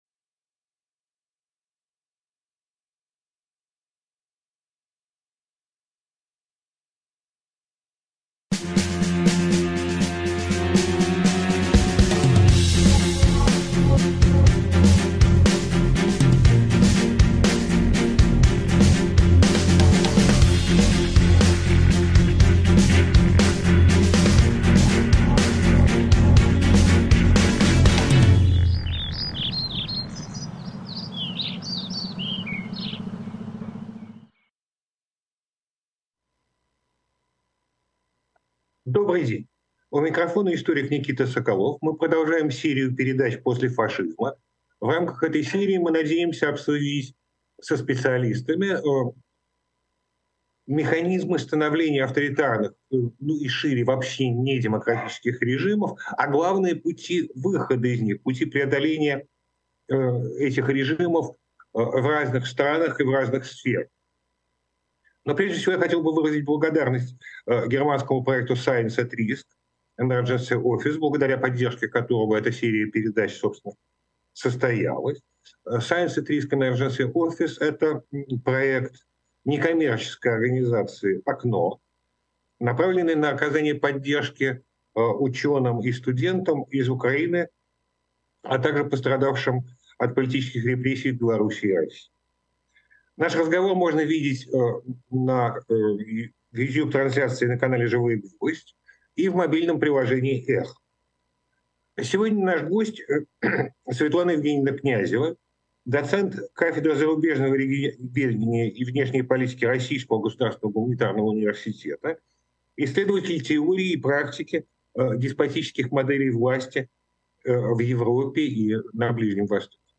Эфир от 30.07.23